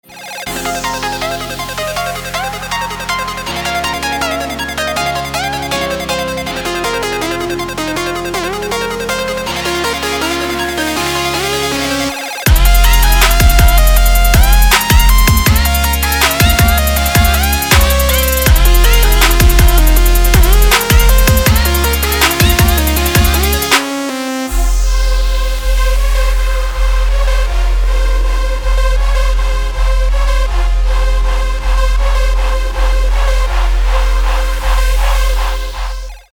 • Качество: 224, Stereo
Trap
Bass